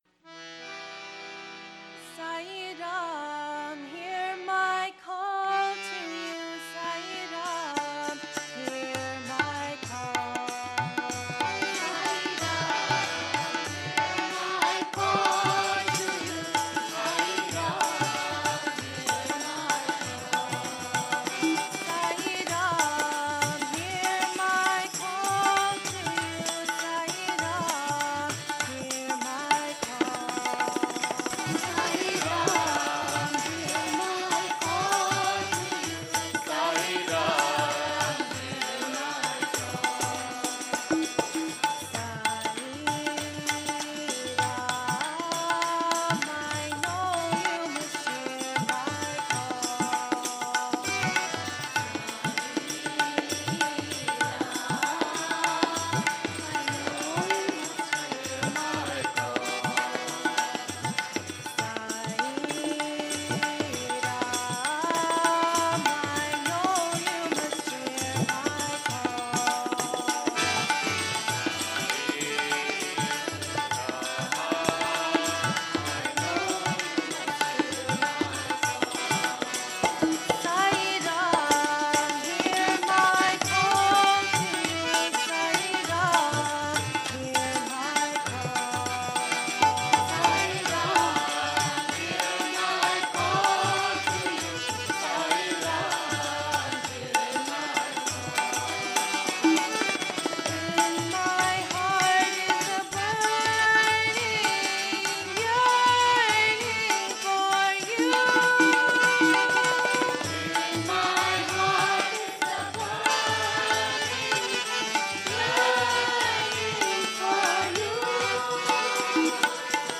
1. Devotional Songs
Minor (Natabhairavi)
8 Beat / Keherwa / Adi